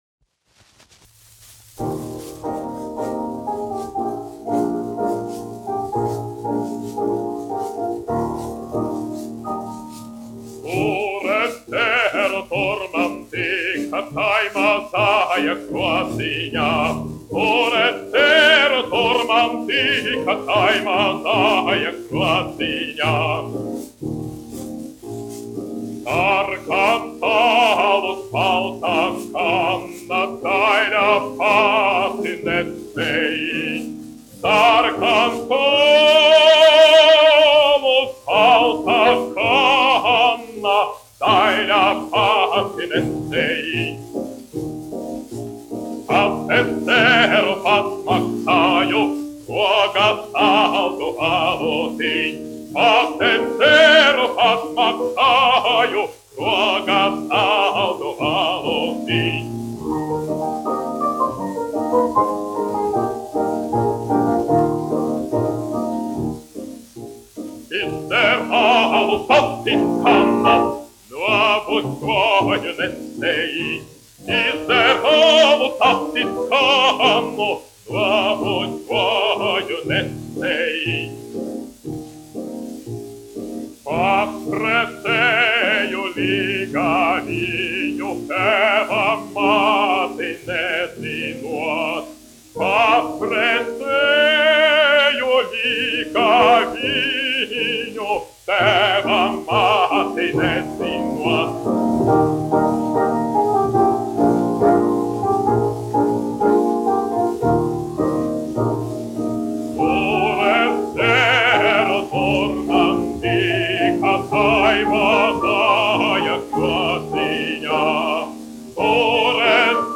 1 skpl. : analogs, 78 apgr/min, mono ; 25 cm
Latviešu tautasdziesmas